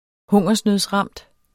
Udtale [ -ˌʁɑmˀd ]